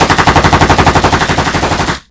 PIANETA GRATIS - Audio/Suonerie - Armi - Pagina1